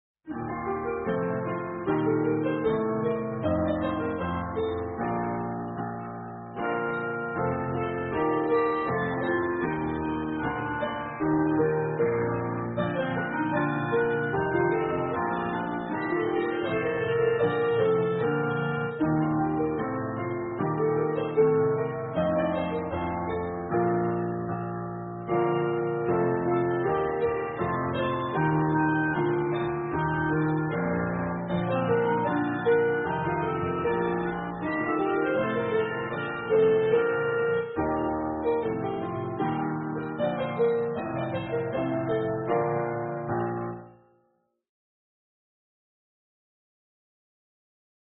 跟我走伴奏-齊唱.mp3